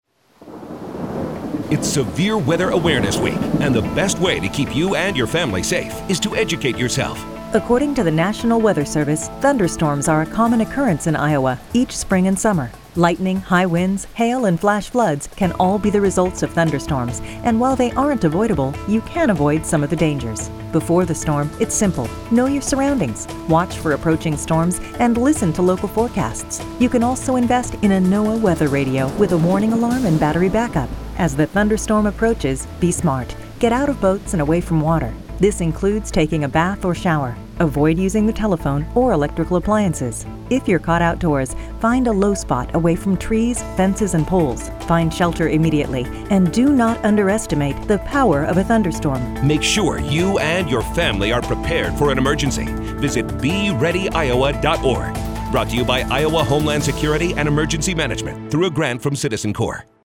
PSA_SWAW_Thunderstorms.mp3